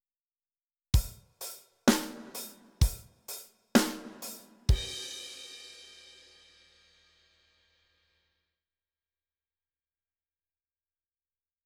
167ページ：ドラムパターン1
Drums-Training-1.wav